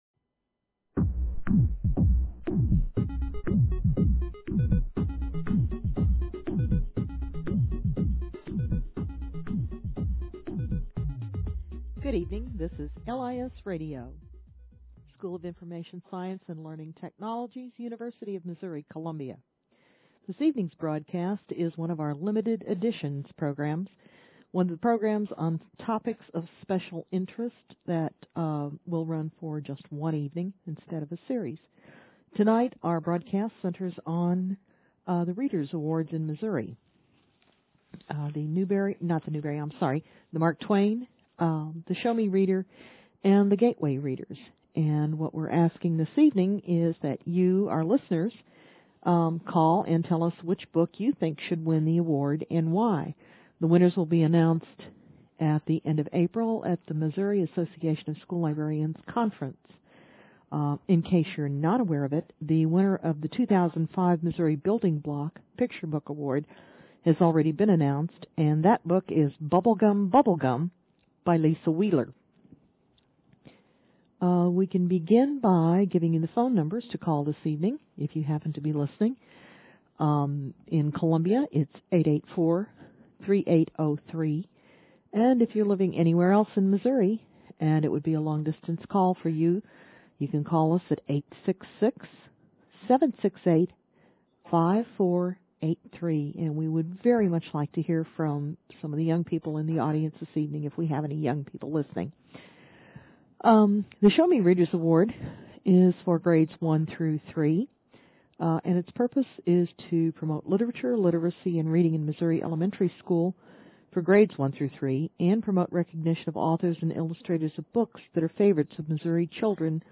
This is a short discussion of the Missouri Reader Award nominees.